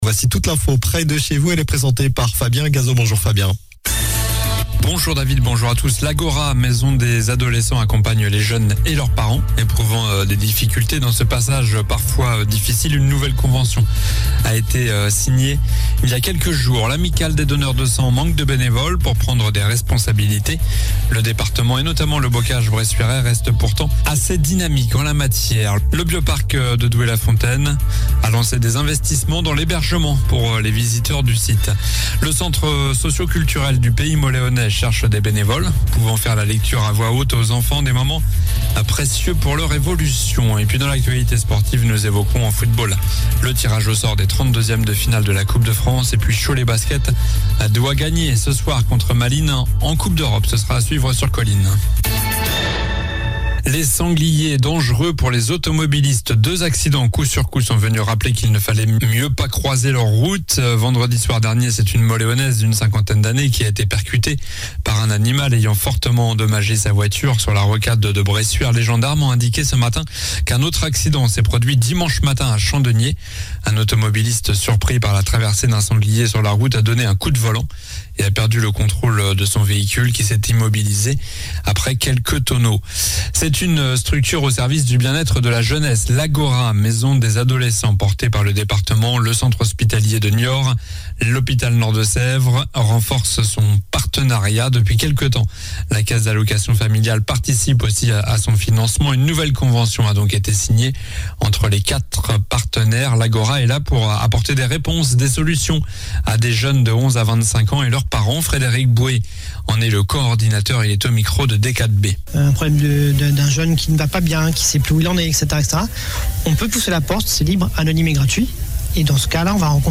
Journal du mardi 22 novembre (midi)